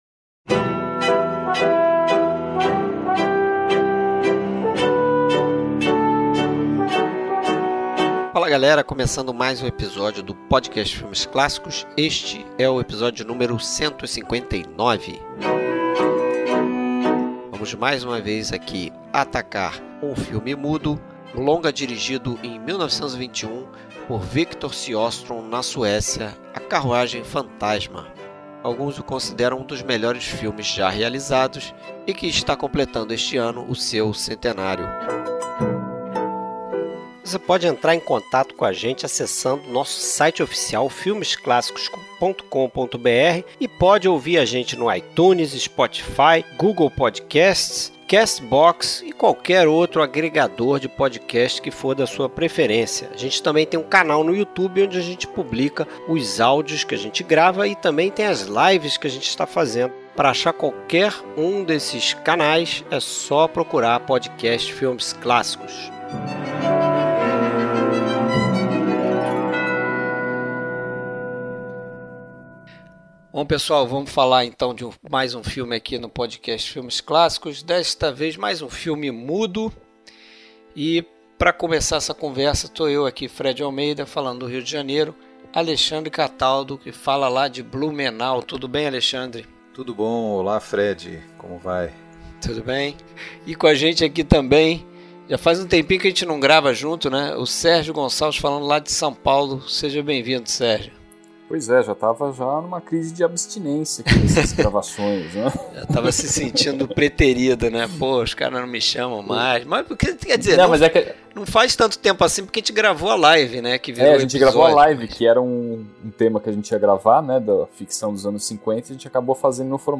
Dirigido e estrelado por Victor Sjöström e baseado num romance homônimo da também sueca Selma Lagerlöff, o filme impressiona por sua narrativa complexa em flashback e seu uso criativo da múltipla exposição para contar uma história de fantasmas e redenção, inspirada por uma lenda inglesa que sugere o que acontece com o último ser humano que morre a cada ano sem ter aproveitado de forma proveitosa sua própria vida. Trilha Sonora: Trilha sonora composta para a restauração do filme.